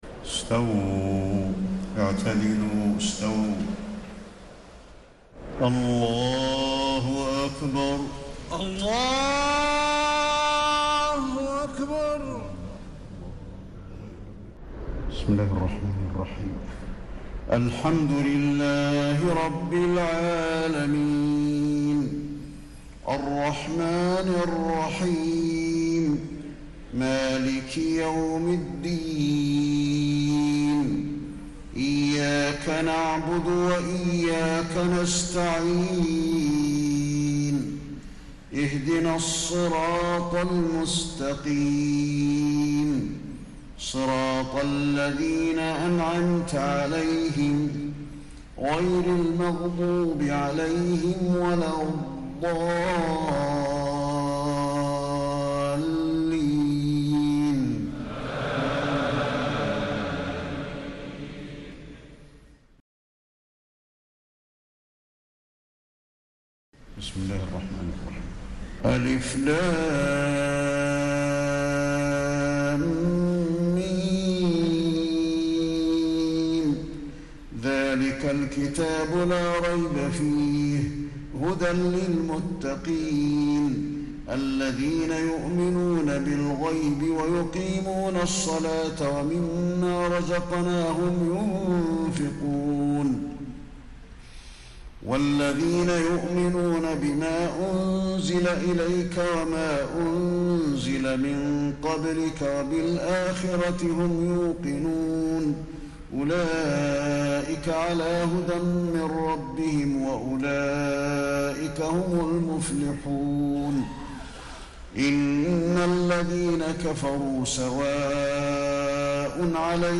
تراويح الليلة الأولى رمضان 1432هـ من سورة البقرة (1-77) Taraweeh 1st night Ramadan 1432H from Surah Al-Baqara > تراويح الحرم النبوي عام 1432 🕌 > التراويح - تلاوات الحرمين